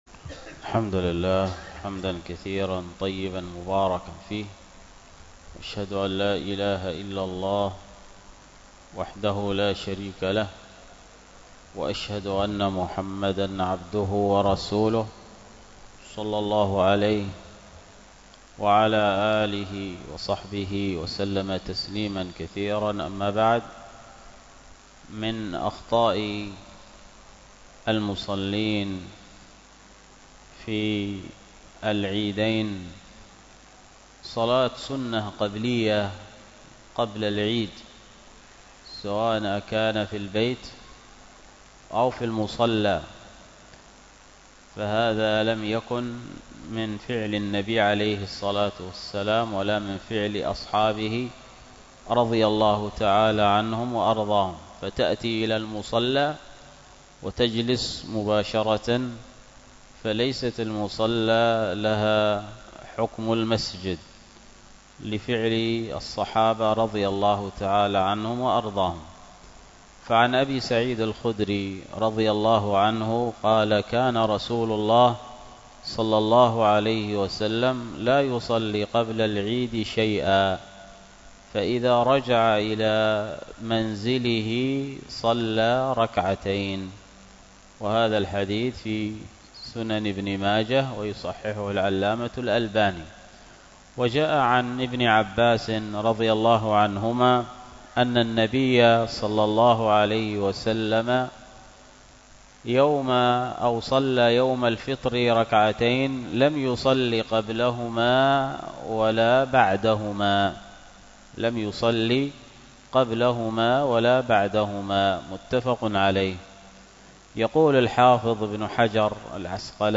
الدرس في مكفرات الذنوب 6